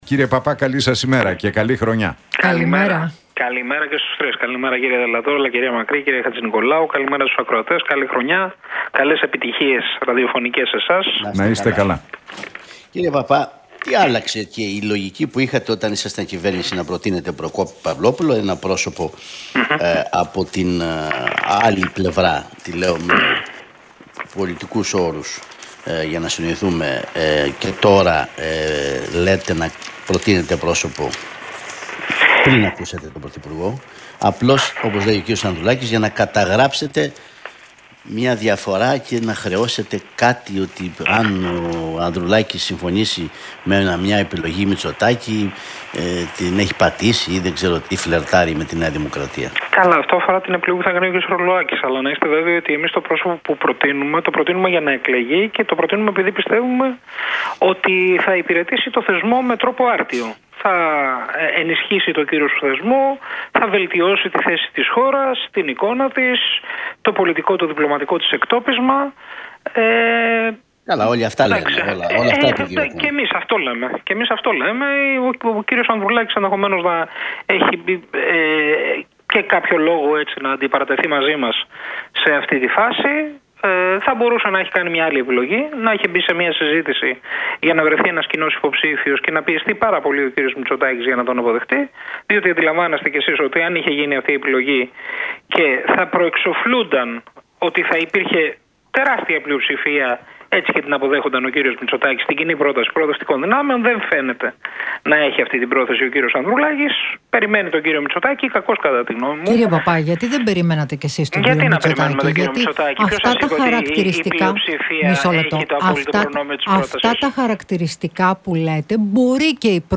Για τη συζήτηση σχετικά με την Προεδρία της Δημοκρατίας και τη στάση του ΠΑΣΟΚ μίλησε μεταξύ άλλων ο Κοινοβουλευτικός Εκπρόσωπος του ΣΥΡΙΖΑ, Νίκος Παππάς στον